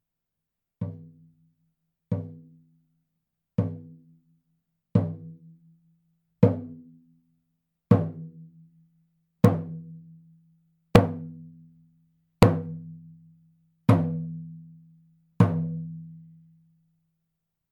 ネイティブ アメリカン（インディアン）ドラム NATIVE AMERICAN (INDIAN) DRUM 16インチ（deer 鹿）
ネイティブアメリカン インディアン ドラムの音を聴く
乾いた張り気味の音です 温度・湿度により皮の張り（音程）が大きく変化します